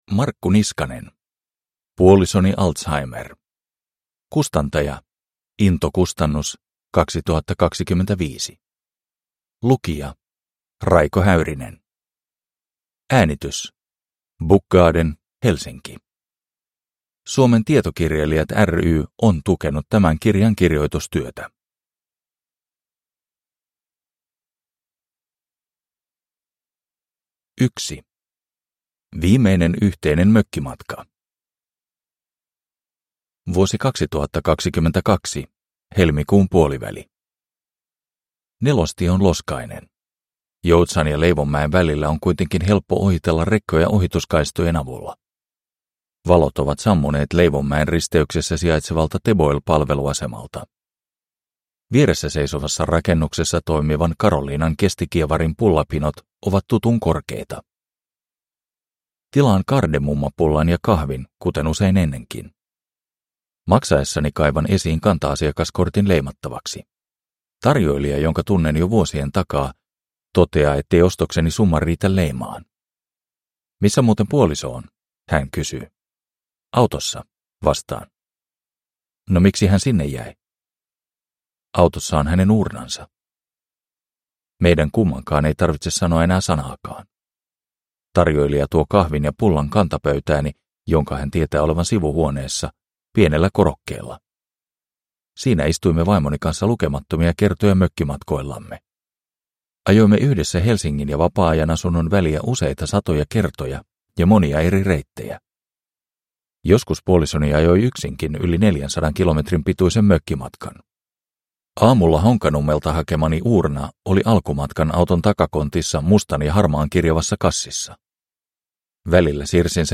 Puolisoni Alzheimer – Ljudbok